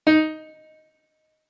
MIDI-Synthesizer/Project/Piano/43.ogg at 51c16a17ac42a0203ee77c8c68e83996ce3f6132